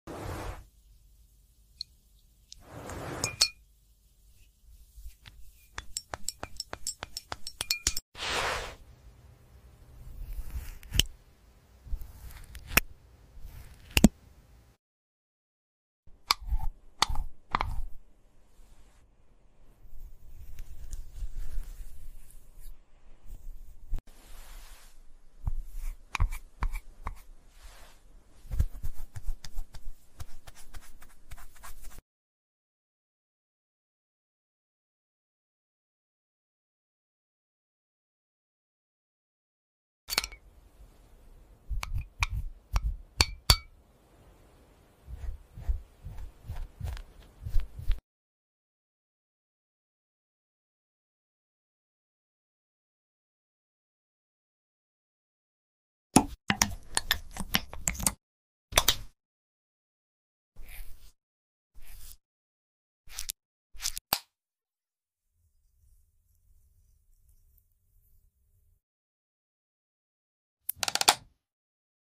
Some ASMR To Help You Sound Effects Free Download